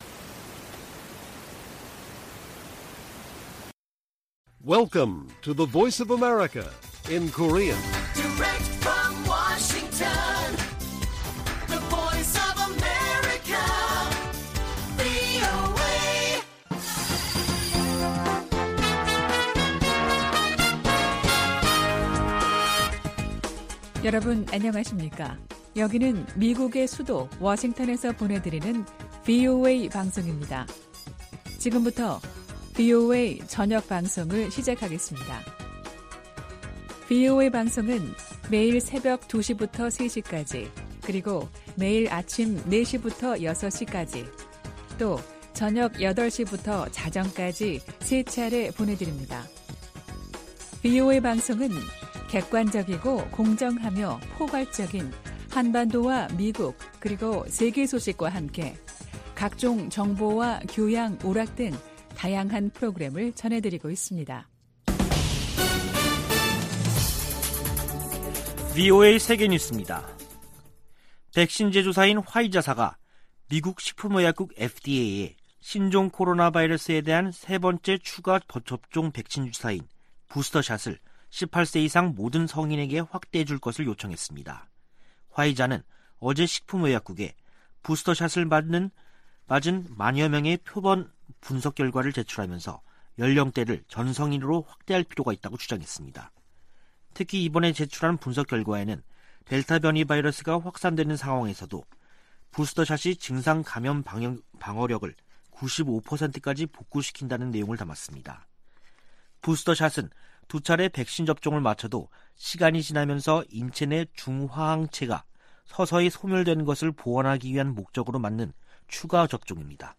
VOA 한국어 간판 뉴스 프로그램 '뉴스 투데이', 2021년 11월 10일 1부 방송입니다. 미 국방부가 북한 문제에 중국의 역할을 다시 강조하며 유엔 안보리 제재 실행을 촉구했습니다. 미 의회의 올해 공식 의정활동이 다음달 중순 종료되는 가운데, 총 11건의 한반도 법안과 결의안이 상·하원에 계류 중입니다.